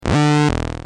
Free MP3 vintage Korg PS3100 loops & sound effects 4
Korg - PS-3100 61